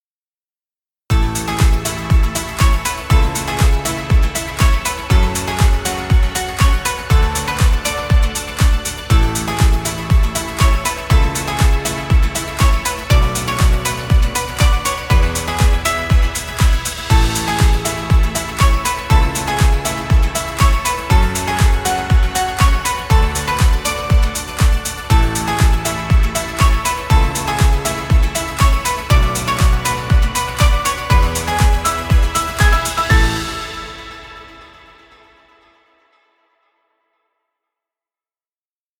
Fun motivational music. Background music Royalty Free.